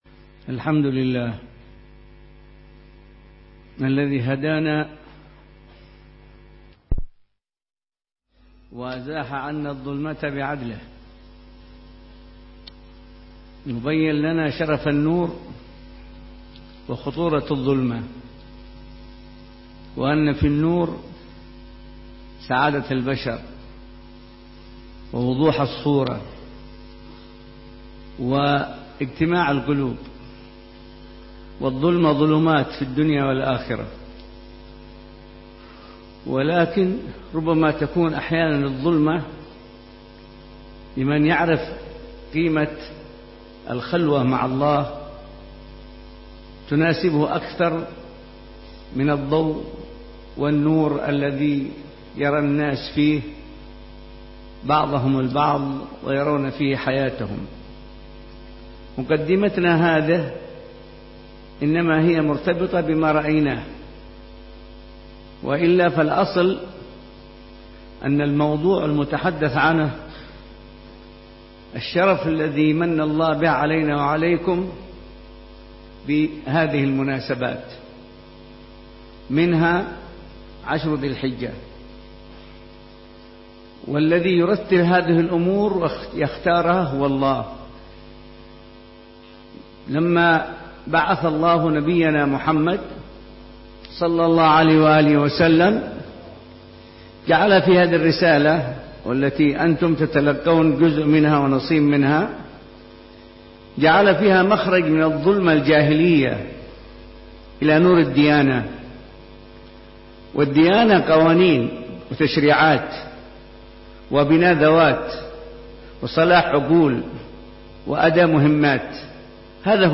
محاضرة
بجلسة الإثنين في دار المصطفى بتريم للدراسات الإسلامية